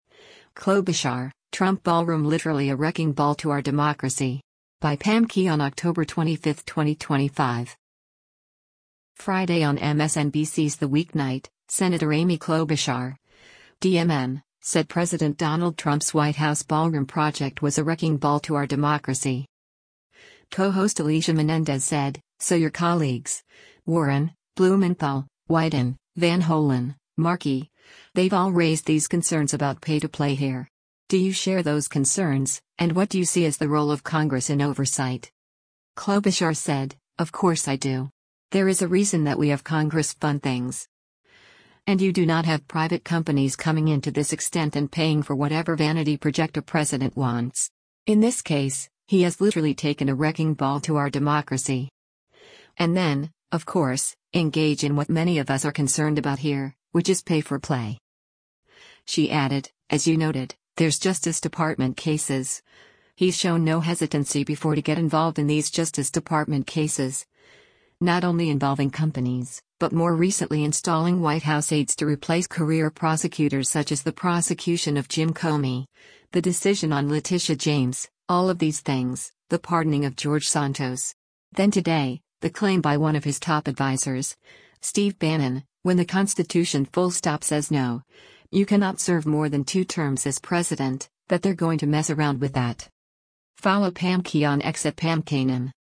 Friday on MSNBC’s “The Weeknight,” Sen. Amy Klobuchar (D-MN) said President Donald Trump’s White House ballroom project was “a wrecking ball to our democracy.”